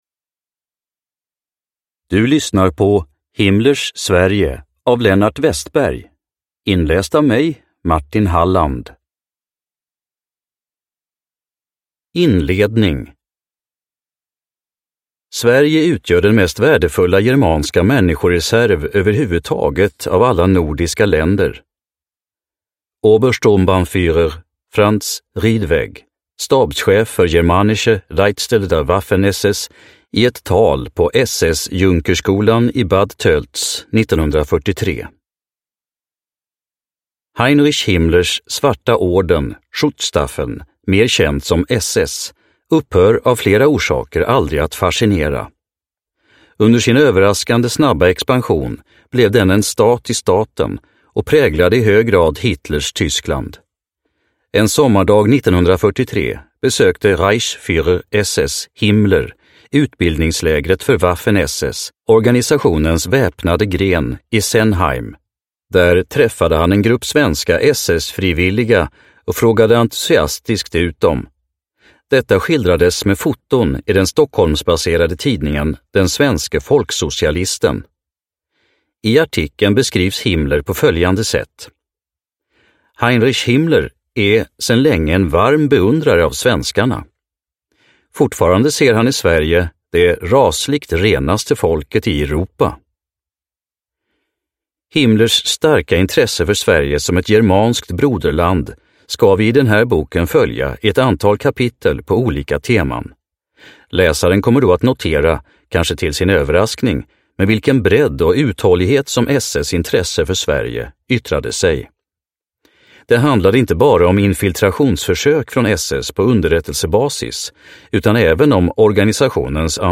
Himmlers Sverige (ljudbok) av Lennart Westberg | Bokon